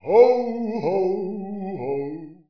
good_hohoho.wav